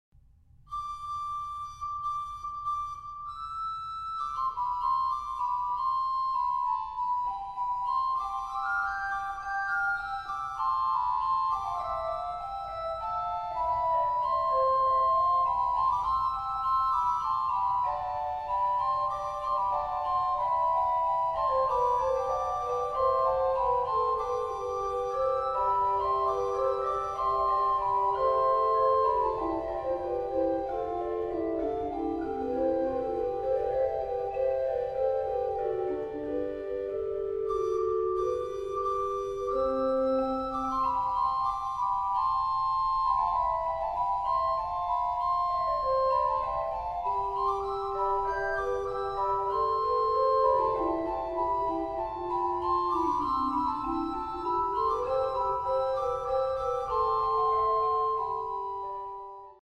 orgue